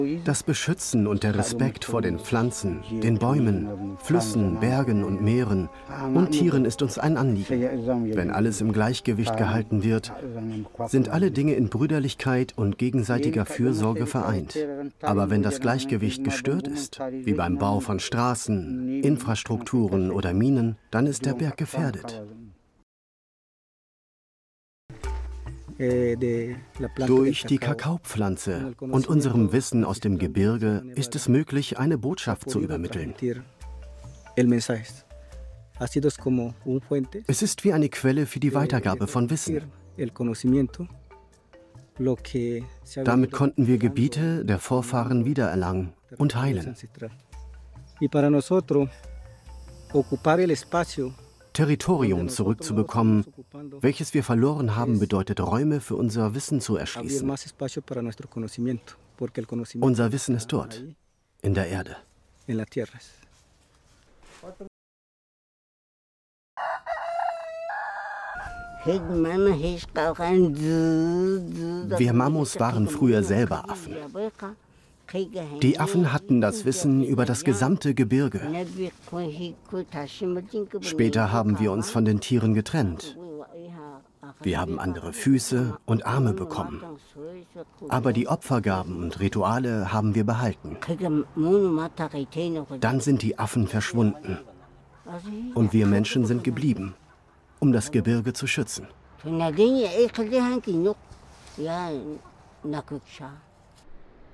sehr variabel
Mittel minus (25-45)
Doku